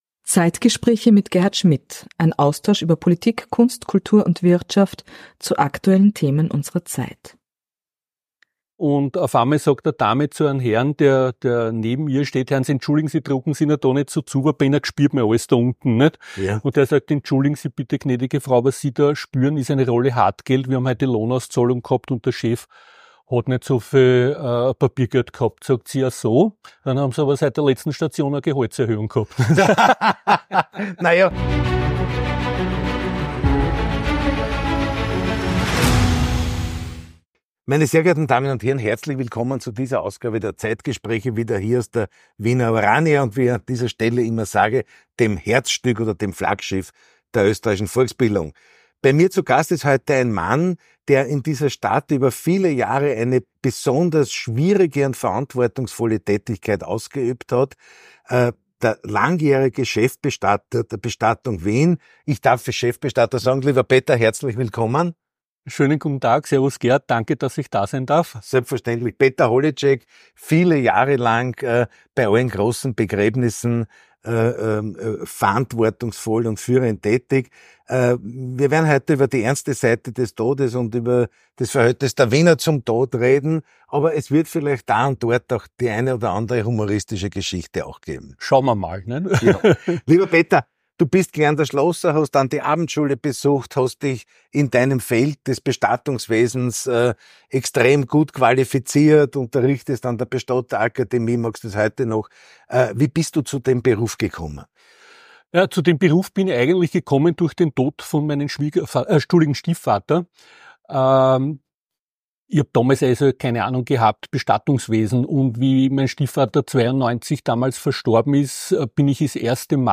Das Gespräch suchen und finden.